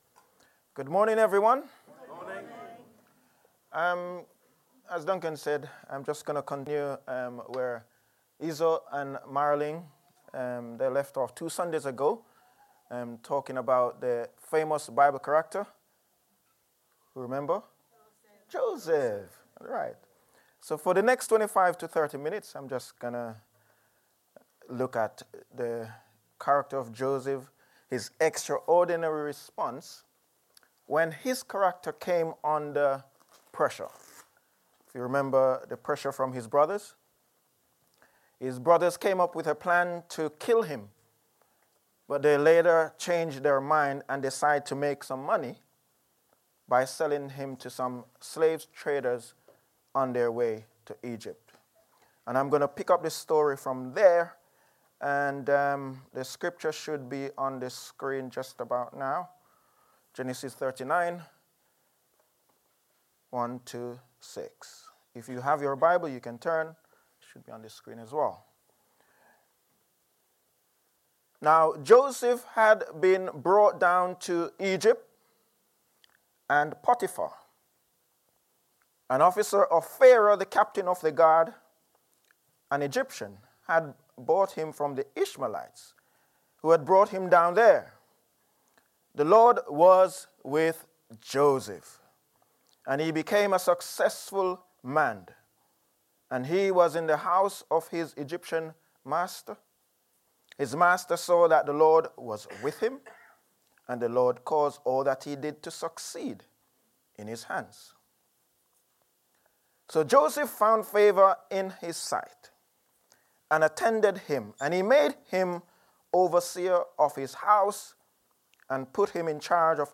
Download Joseph: Rise To Power | Sermons at Trinity Church